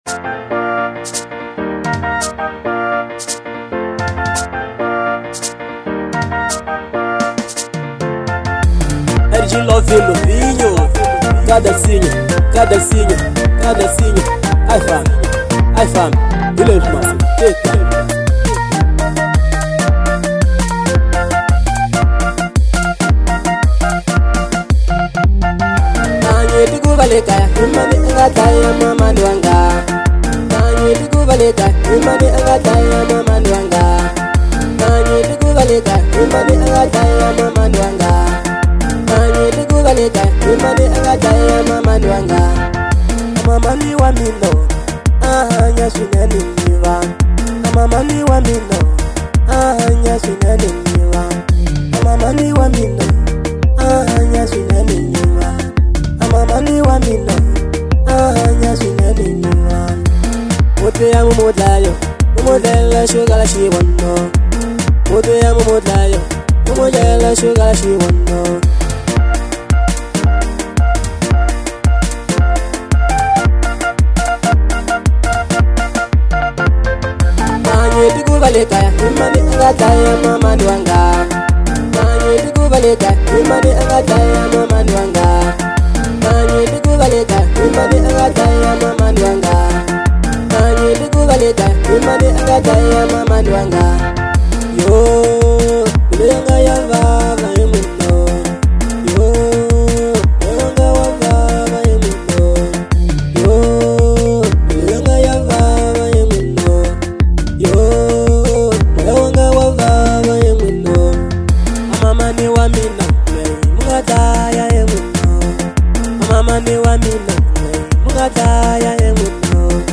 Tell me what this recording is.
Genre : Marrabenta